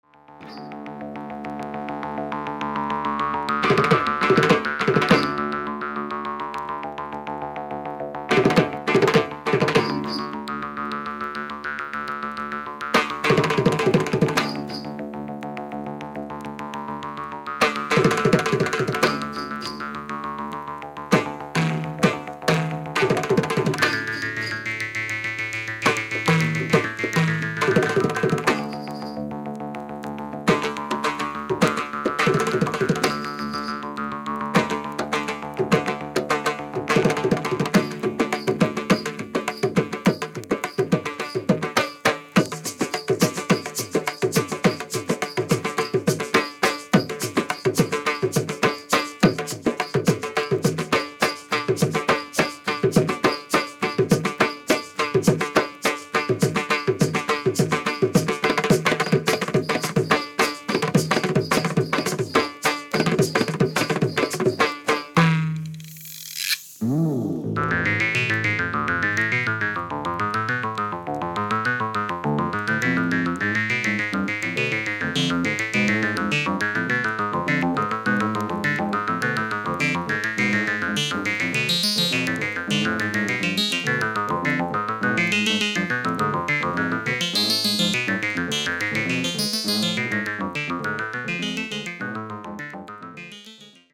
インド・パーカッション奏者